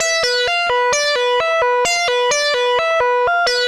Index of /musicradar/80s-heat-samples/130bpm
AM_CopMono_130-E.wav